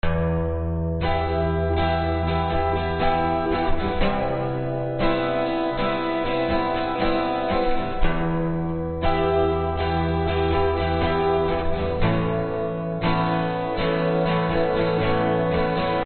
描述：原声蓝调/民谣吉他，速度在126/63BPM左右。
标签： 原声 布鲁斯 民谣 吉他
声道立体声